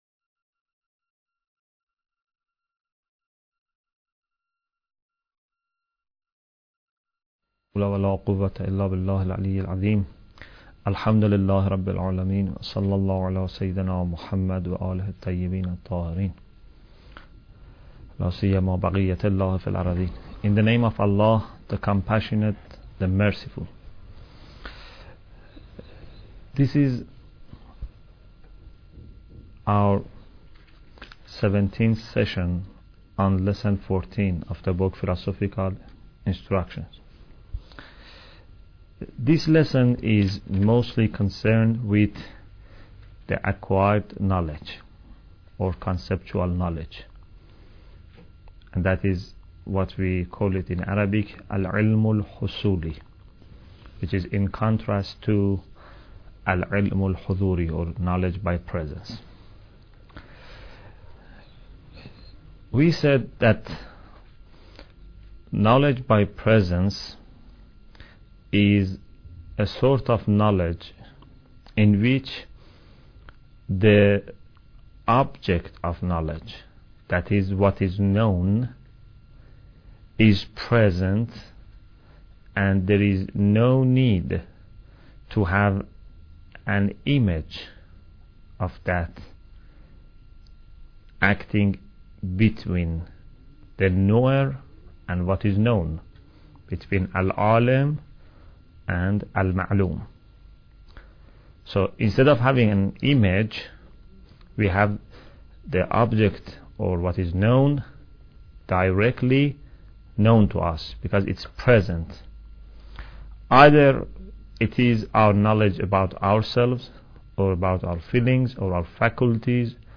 Bidayat Al Hikimah Lecture 17